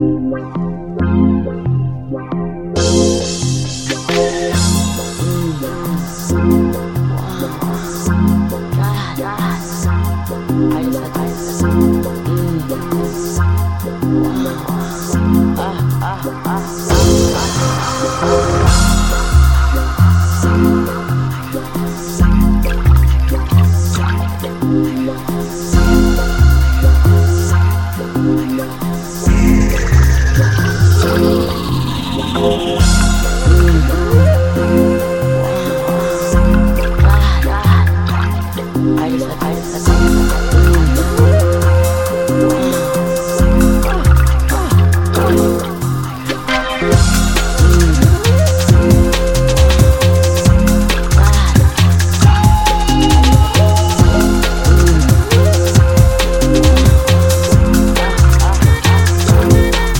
Электронная
Дебютный альбом легенд английского прогрессив-хауса.